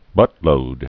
(bŭtlōd)